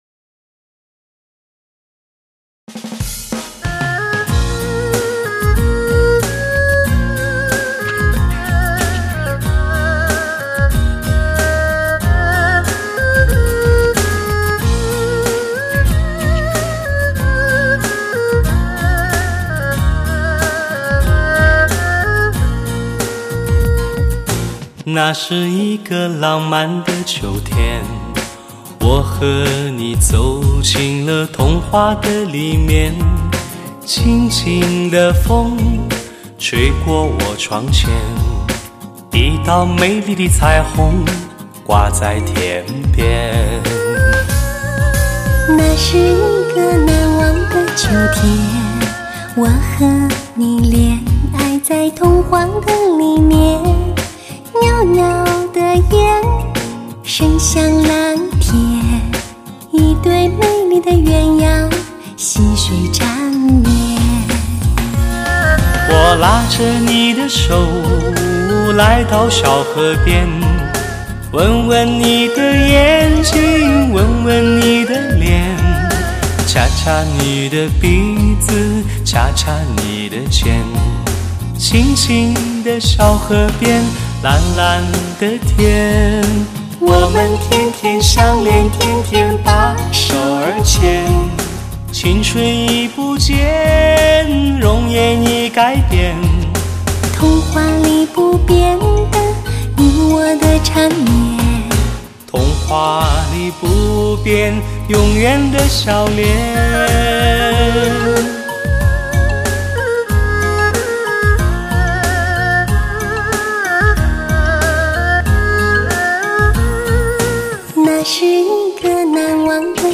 类型: 汽车音乐